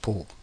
Ääntäminen
Ääntäminen France (Paris): IPA: /po/ Haettu sana löytyi näillä lähdekielillä: ranska Käännöksiä ei löytynyt valitulle kohdekielelle.